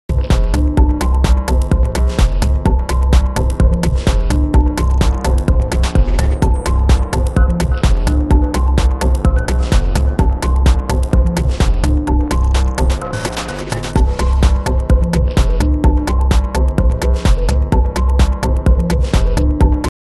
リズミカルなハット＆ドラムに、響きの良いヴィブラフォン！